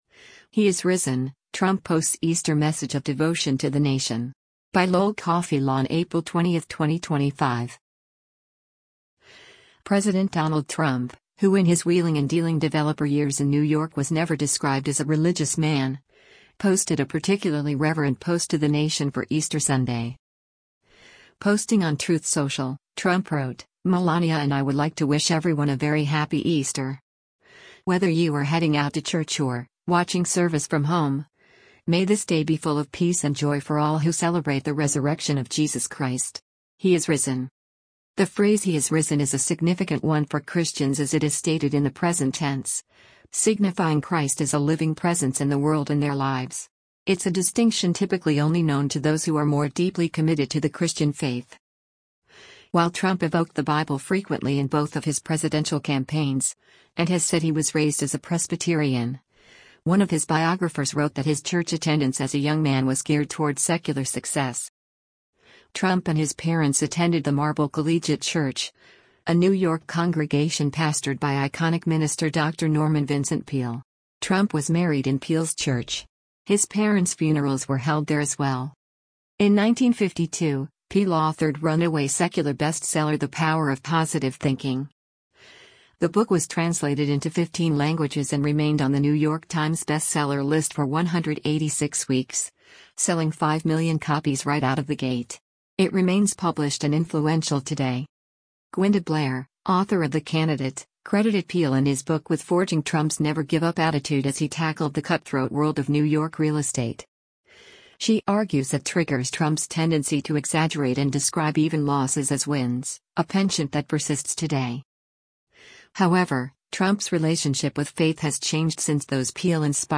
U.S. President Donald Trump speaks at the National Prayer Breakfast at the U.S. Capitol on